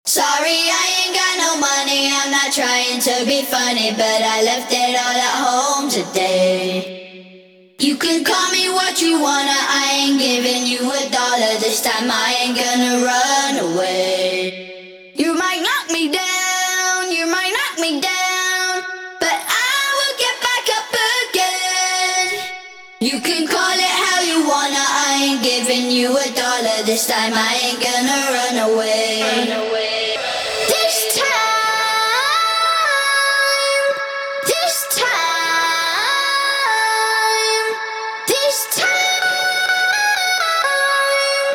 Studio Acapella